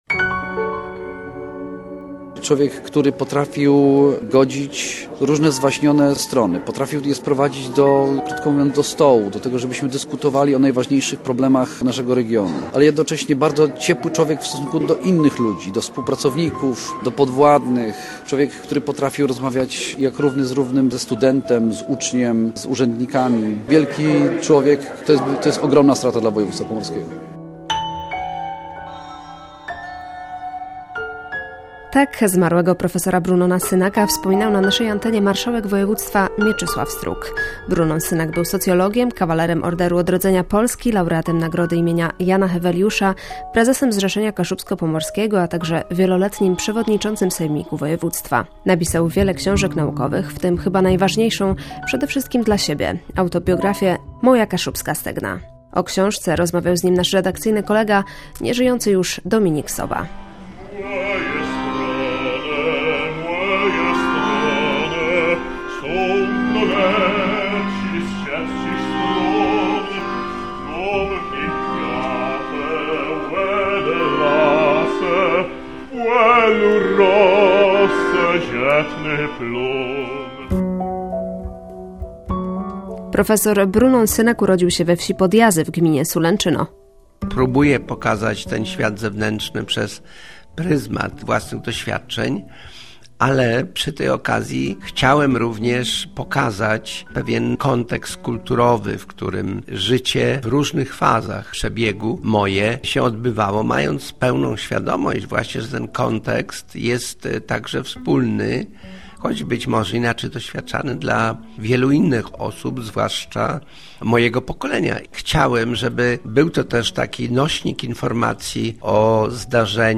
Profesora Synaka wspominają współpracownicy i koledzy, z którymi rozmawiała